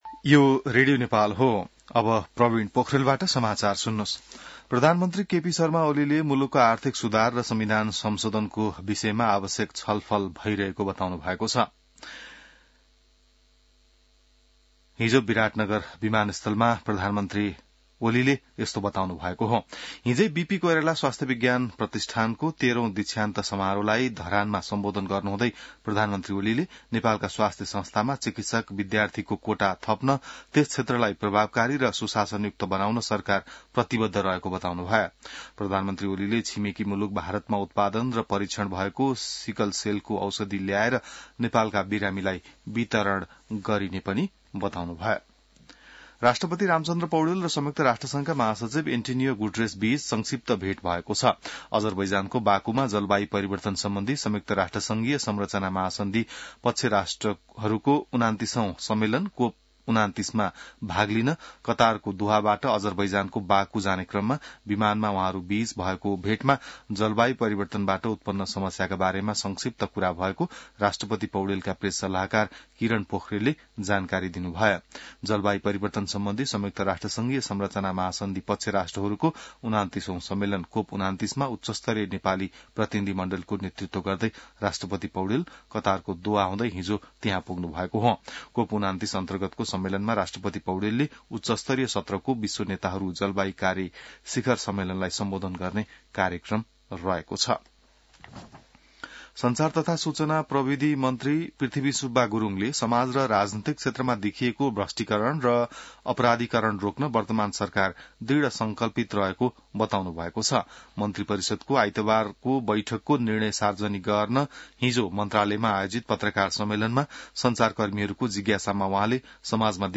बिहान ६ बजेको नेपाली समाचार : २८ कार्तिक , २०८१